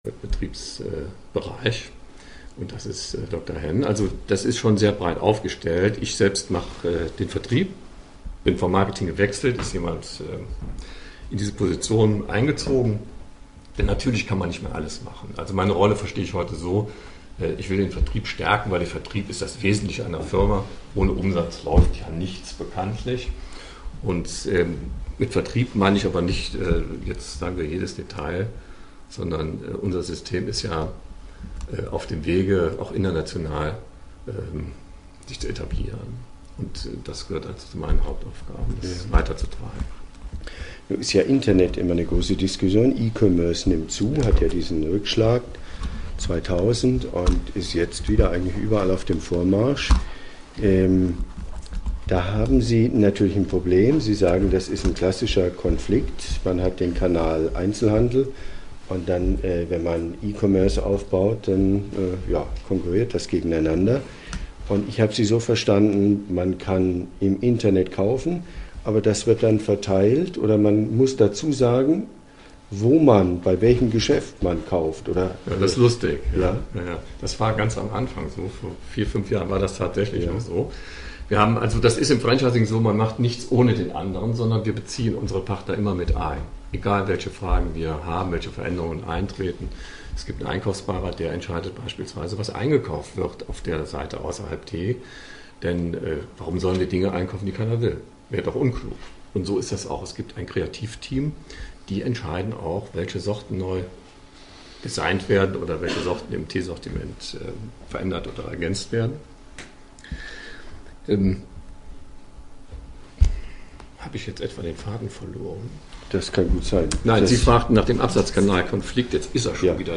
Labor-Interview